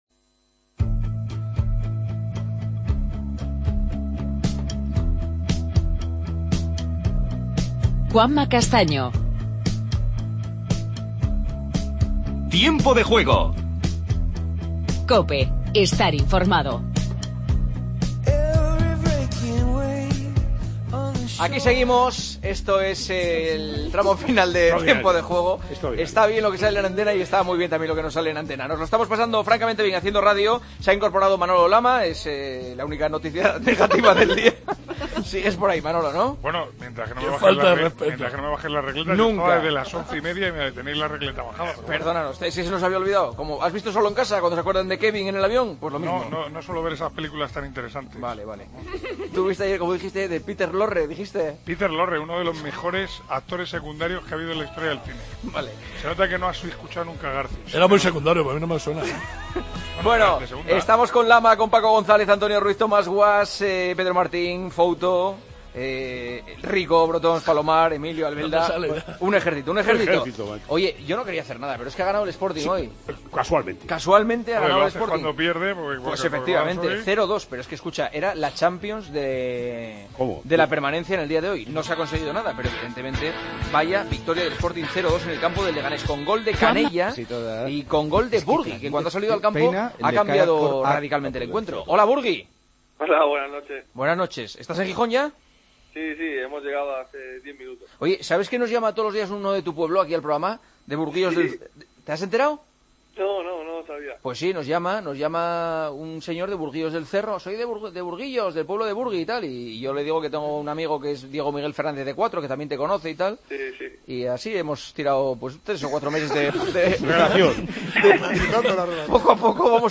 Entrevistas a Burgui